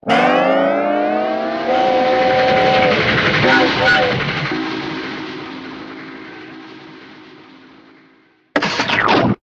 TL_train.ogg